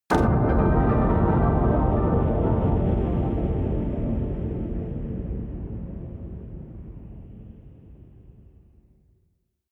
Gemafreie Sounds: Hits/Impacts